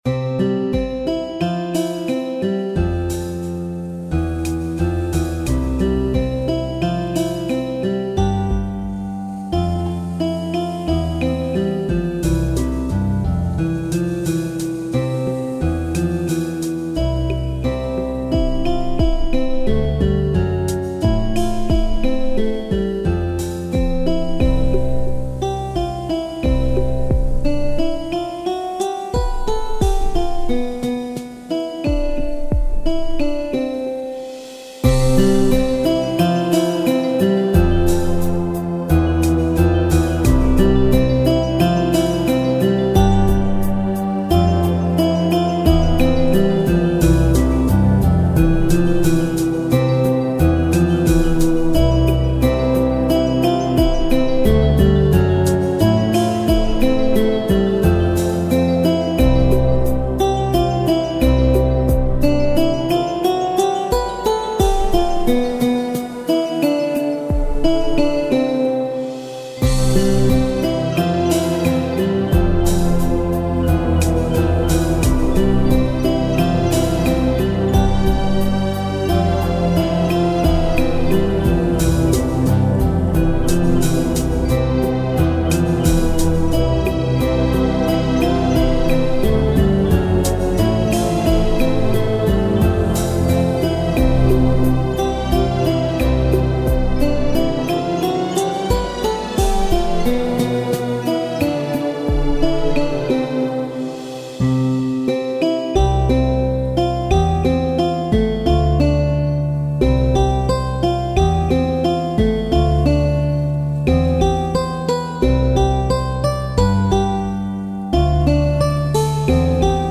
Получился гитарный трек- баллада - для релаксации души и восстановления душевного равновесия...